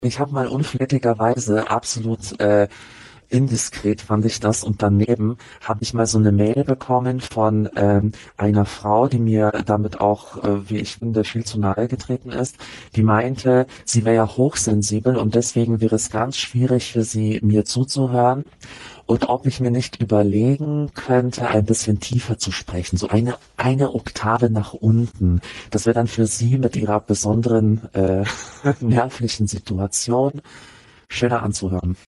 Ich würde es vielleicht nicht so extrem machen